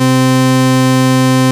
OSCAR 13 C4.wav